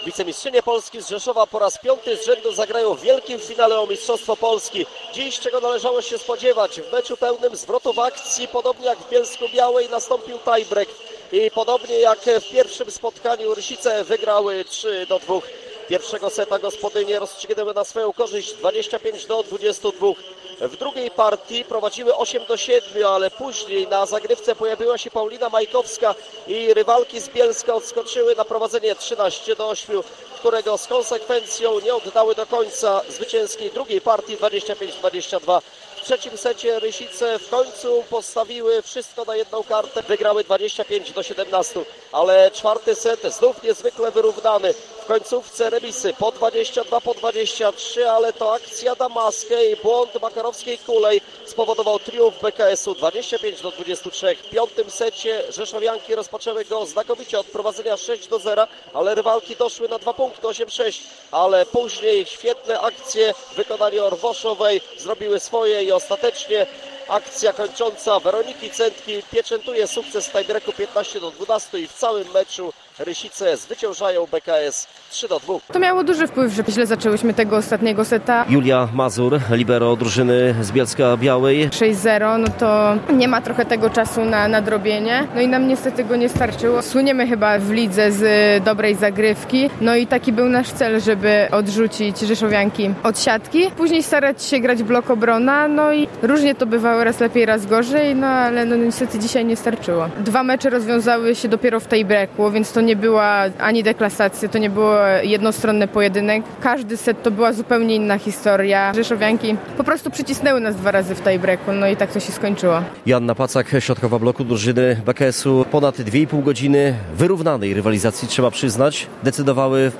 Więcej o tym meczu w relacji